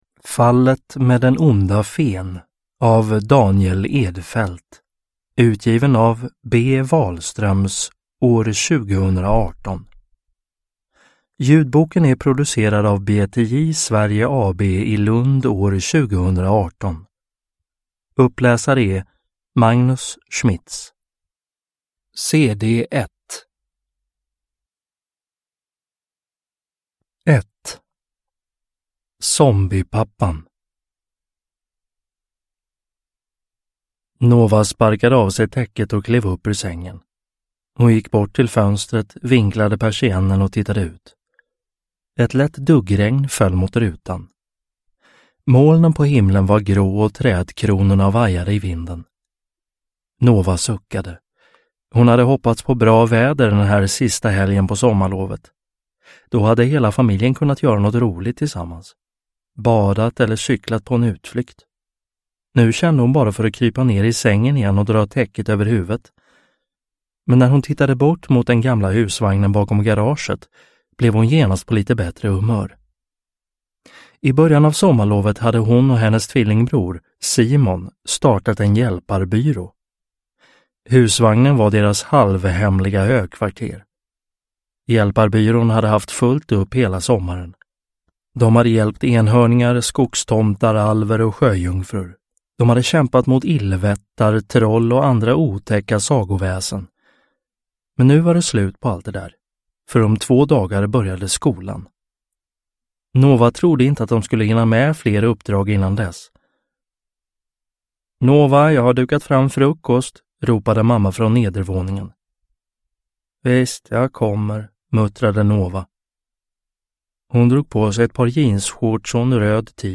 Hjälparbyrån. Fallet med den onda fen – Ljudbok – Laddas ner